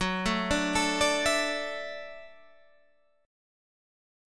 Listen to F#m7#5 strummed